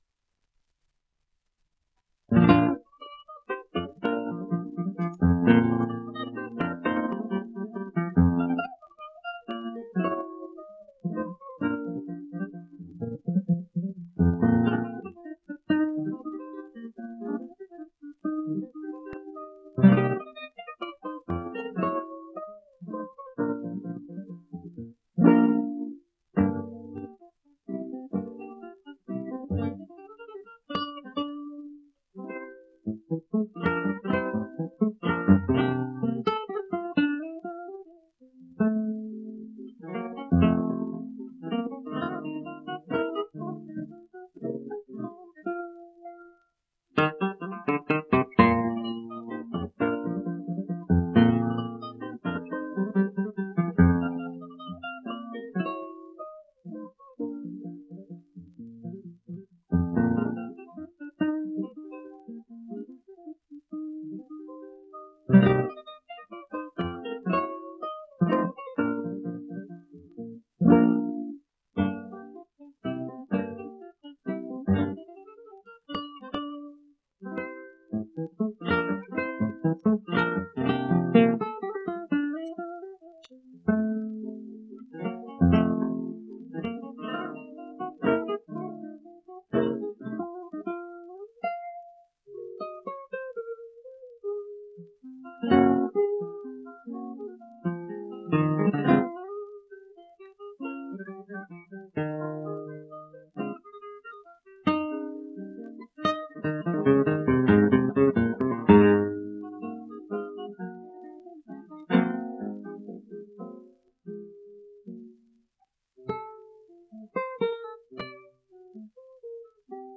1 disco : 78 rpm, mono ; 25 cm.
guitarra